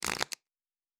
pgs/Assets/Audio/Fantasy Interface Sounds/Cards Shuffle 2_03.wav
Cards Shuffle 2_03.wav